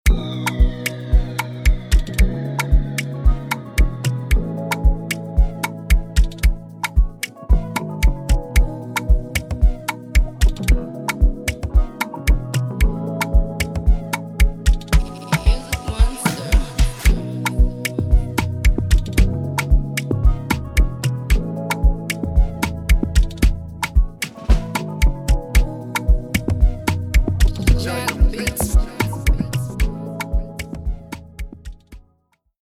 Tempo: 111
Genre: Afrobeats, Afropop, Afro-rave